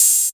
Closed Hats
MB Hi Hat (10).wav